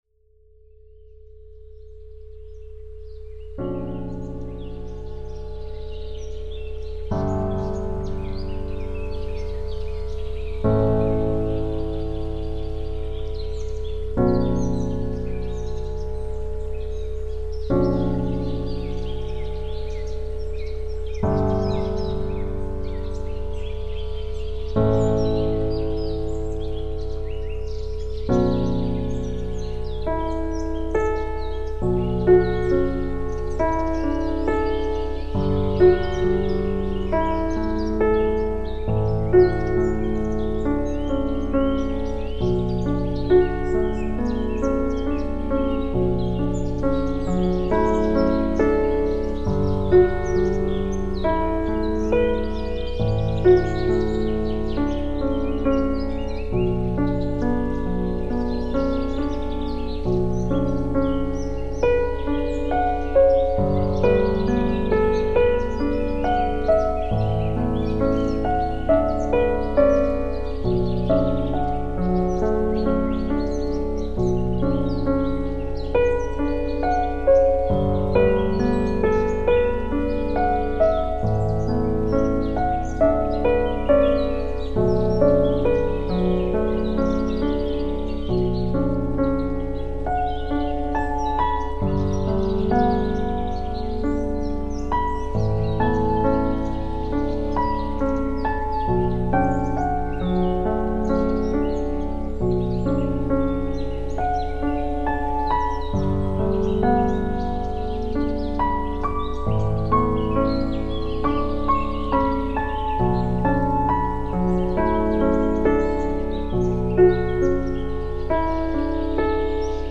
• -1) Quatre fréquences spécifiques, sélectionnées en résonance avec les organes ciblés, pour stimuler leurs fonctions énergétiques et vibratoires.
• -2) Un accompagnement au piano accordé en 432 Hz (titre : Yūgen), fréquence naturelle réputée pour favoriser l’harmonie, la détente et l’alignement intérieur.
• -3) Une ambiance sonore inspirée de la nature (eau, vent, oiseaux…), qui enveloppe l’écoute dans une atmosphère apaisante et immersive.